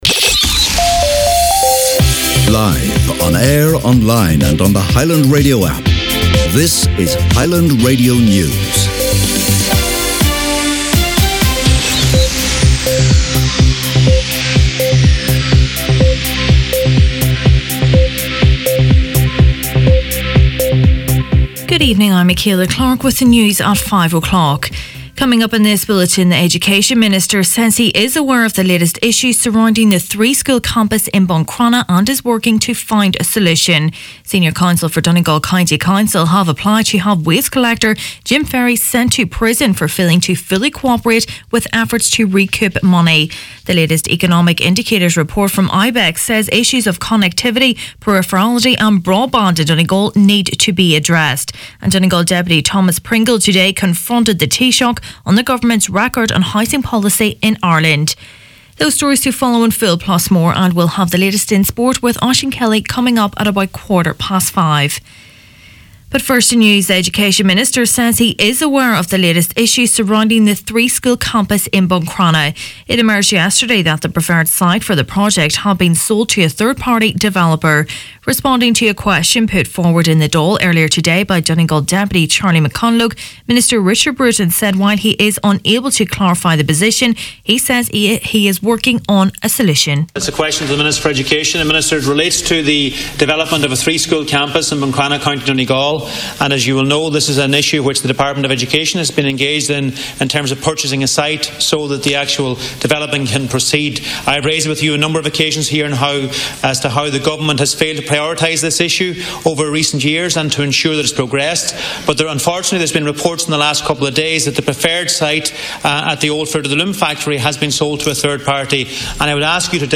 Main Evening News, Sport and Obituaries Wednesday October 3rd